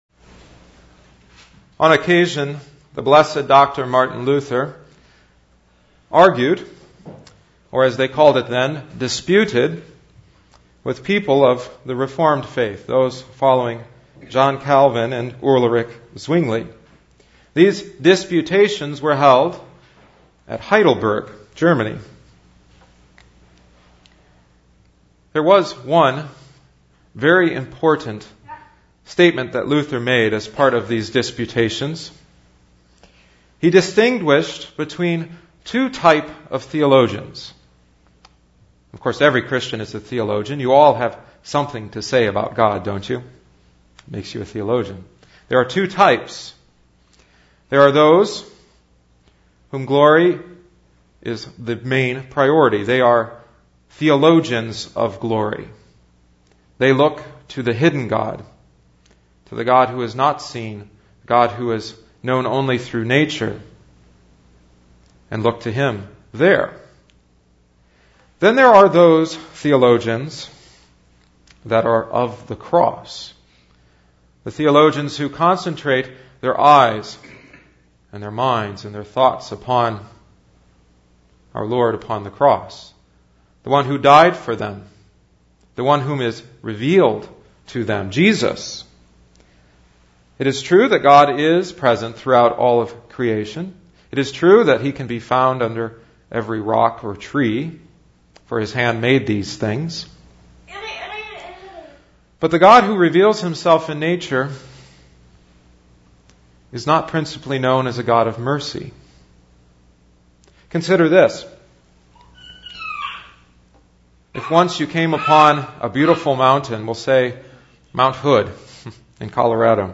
Jesus tells us how the cross is the character of the Christian life and is the source of every blessing, both now in time and then in eternity. Listen to the sermon: Divine Service 2011-11-02